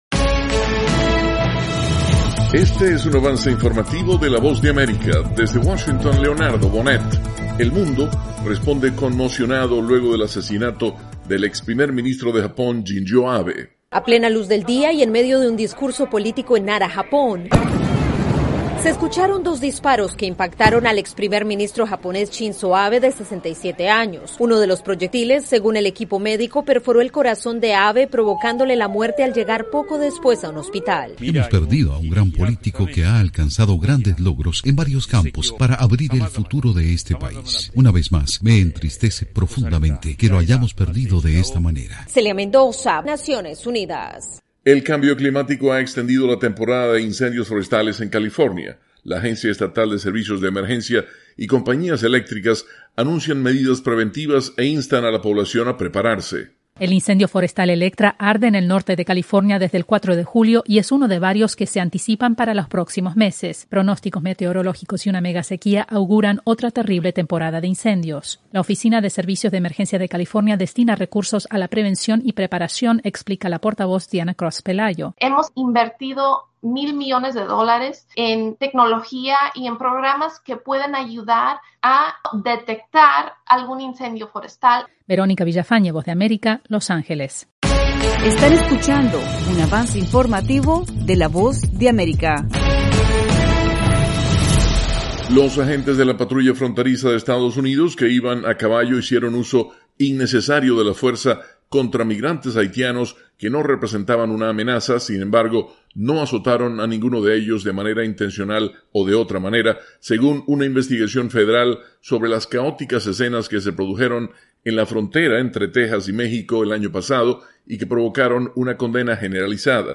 Avance Informativo - 7:00 PM
El siguiente es un avance informativo presentado por la Voz de América, desde Washington